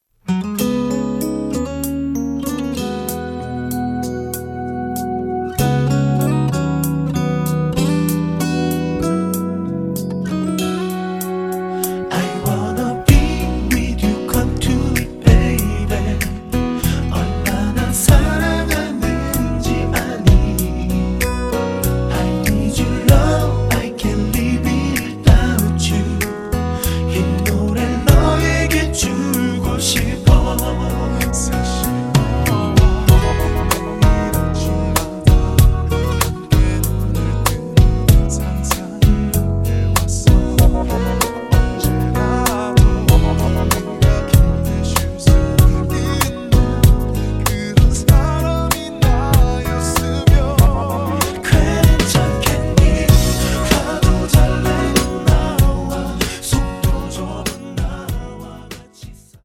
음정 -1키 3:55
장르 가요 구분 Voice MR